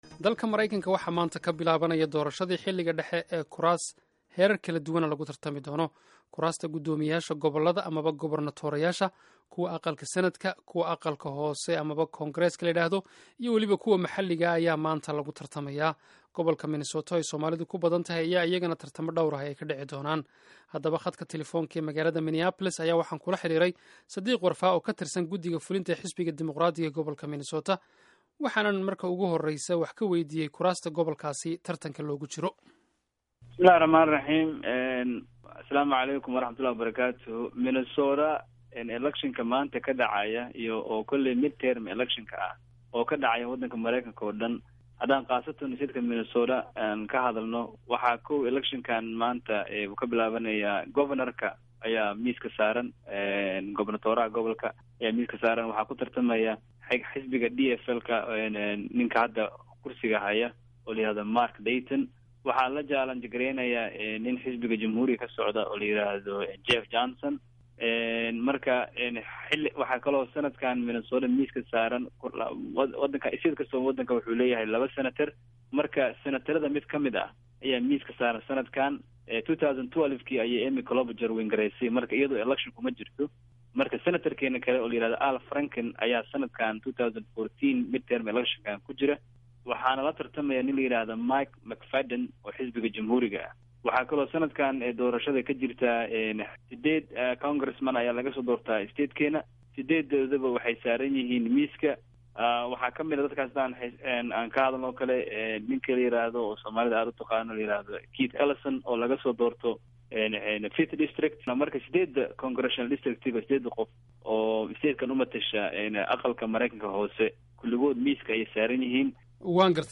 Wareysiga Doorashada Maraykanka